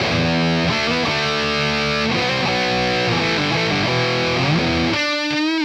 Index of /musicradar/80s-heat-samples/85bpm
AM_RawkGuitar_85-E.wav